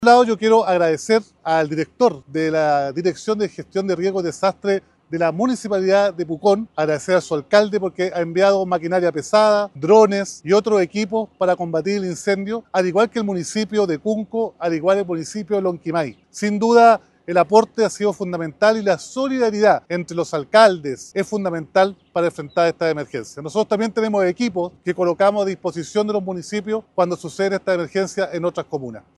Alcalde-Roberto-Neira-agtradece-el-aporte-de-comunas-.mp3